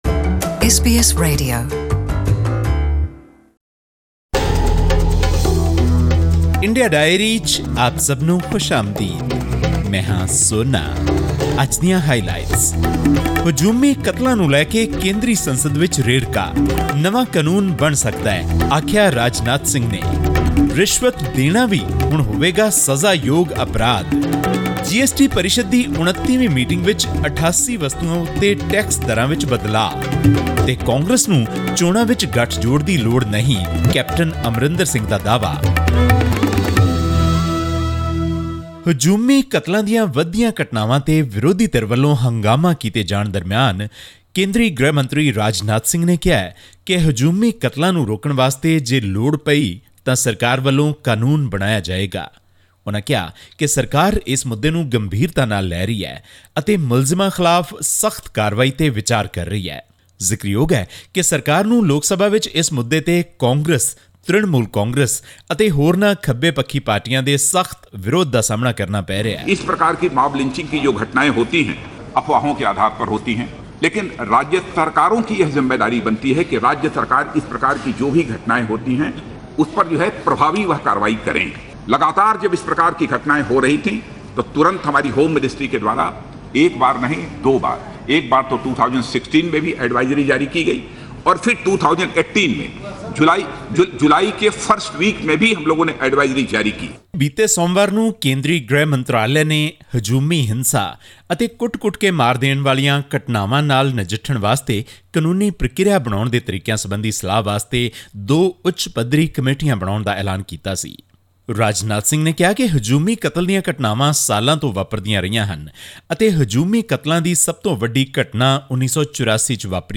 Hear more about this and other major news stories from India, in our weekly round up - India Diary.